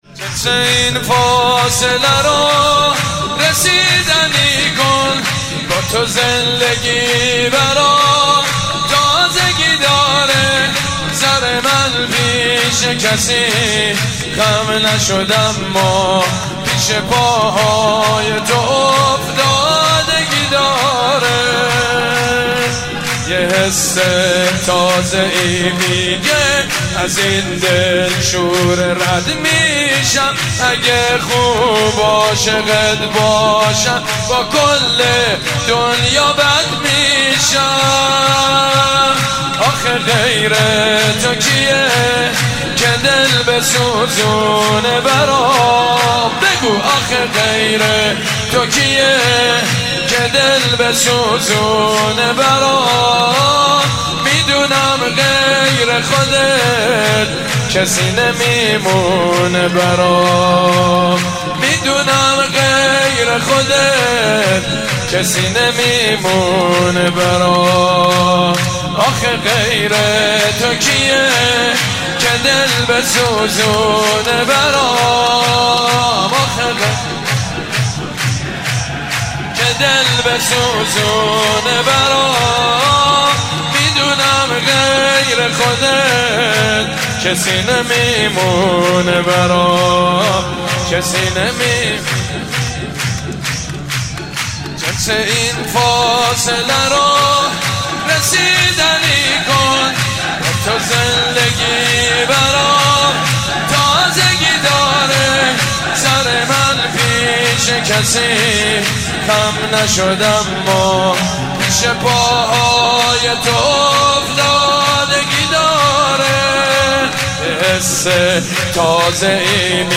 سرود: جنس این فاصله رو رسیدنی کن